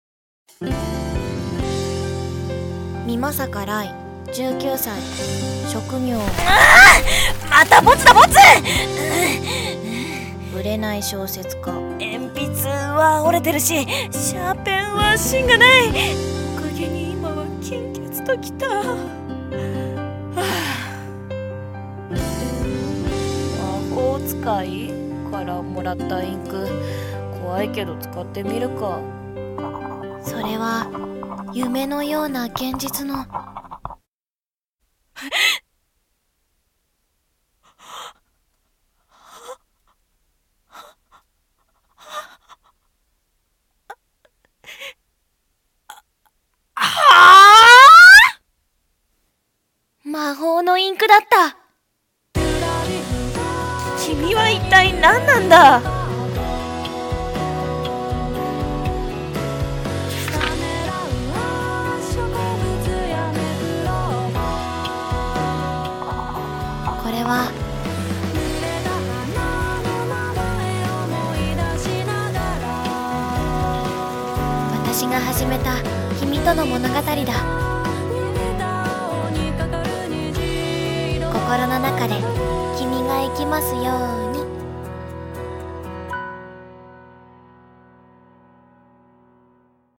【予告風声劇】